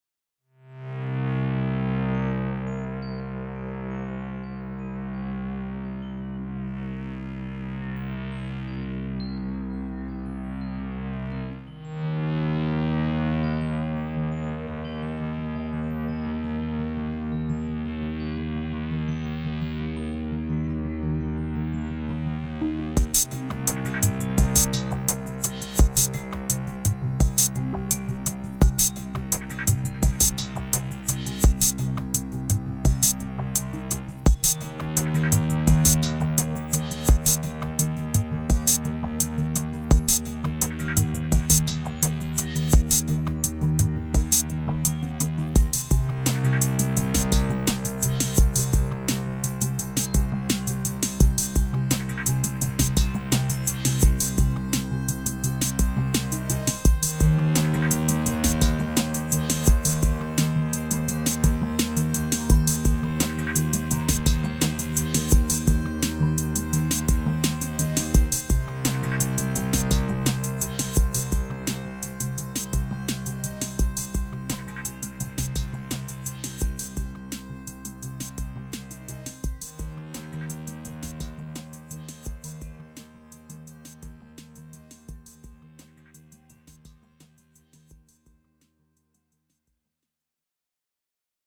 Ebow Elements was created by using an Ebow on the strings of an electric guitar, and the resulting sampled sounds are certainly unique, indeed you will need to listen to the audio demo to make your own conclusion, as for once I struggle to find the vocabulary to offer a conclusive description of what an Ebow sounds like, I did find an interesting little video which I have linked below to explain how they work.
It certainly has electric guitar tonality, but I could equally describe it as a kind of amplified Theremin or violin, it could be used as a lead, however has equal ppossibilities as a low searing bass, or dark malevolent drone, so it has options, and options are always a good thing to have!
ebow_demo.mp3